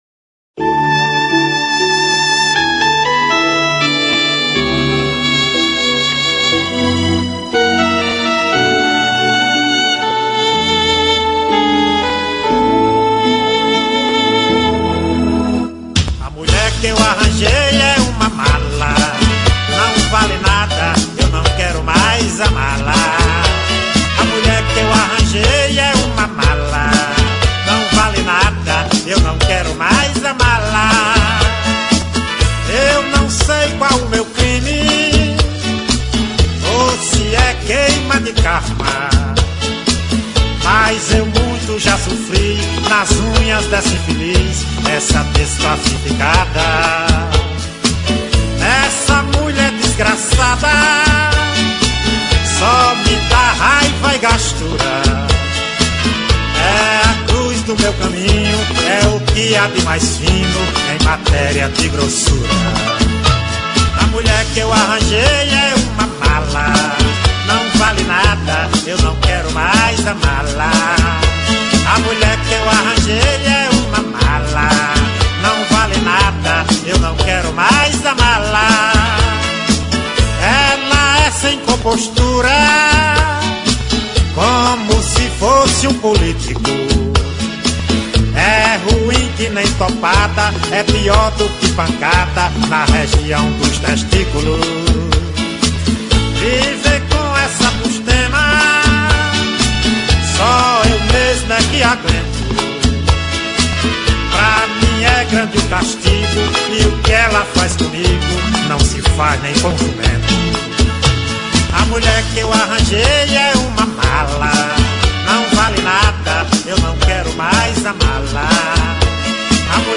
Bregas